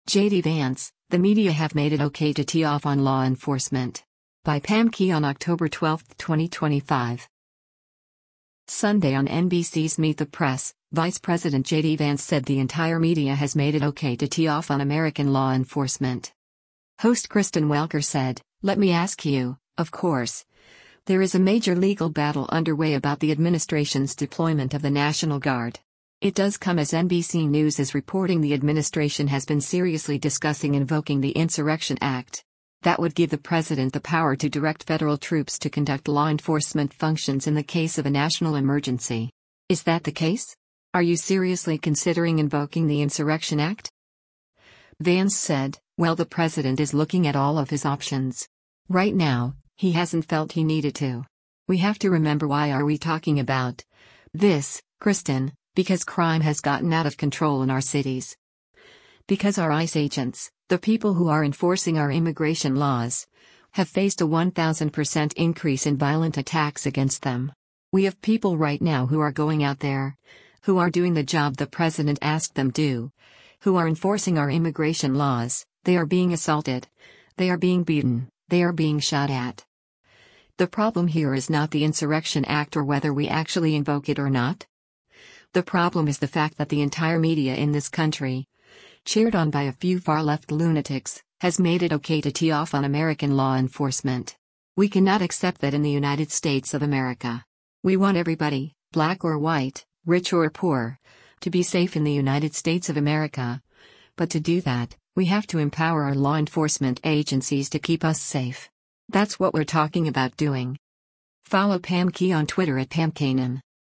Sunday on NBC’s “Meet the Press,” Vice President JD Vance said the “entire media” has made it “OK to tee off on American law enforcement.”